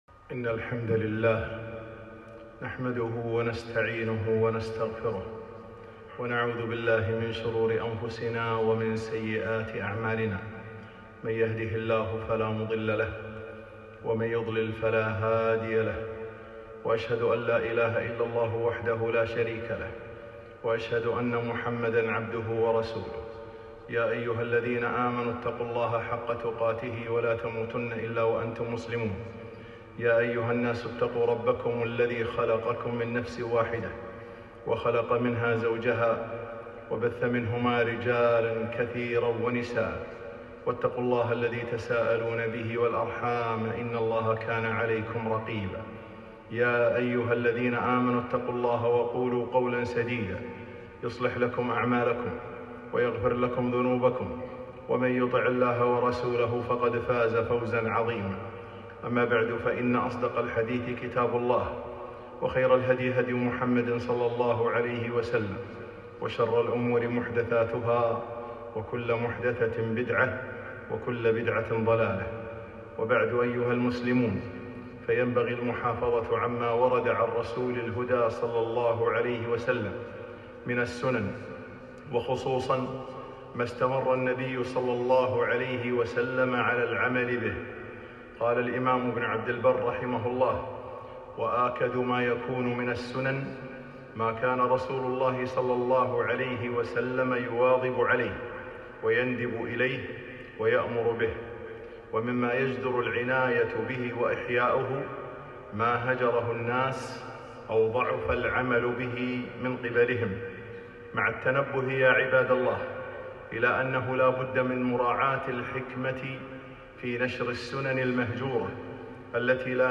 خطبة - إحياءُ السُّننِ والتذكيرُ بها